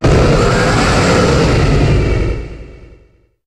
Cri de Primo-Groudon dans Pokémon HOME.